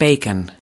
Transcription and pronunciation of the word "bacon" in British and American variants.